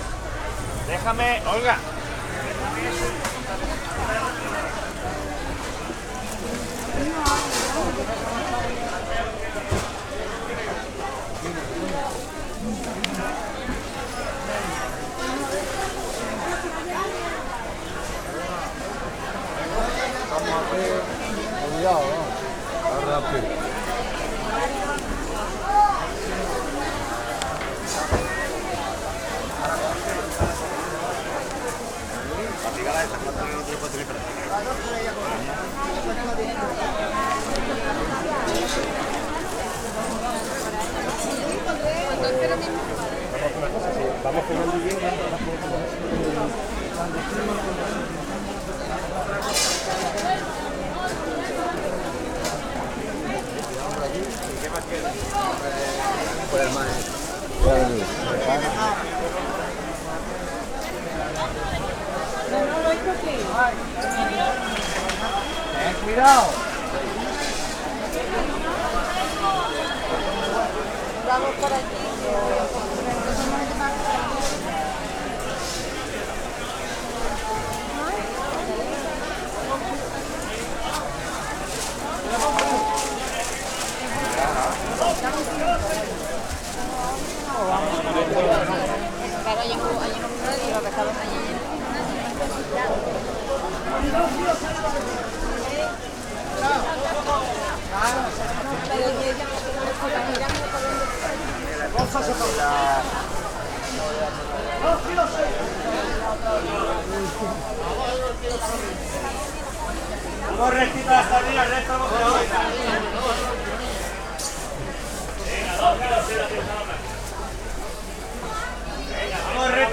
market-3.ogg